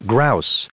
added pronounciation and merriam webster audio
878_grouse.ogg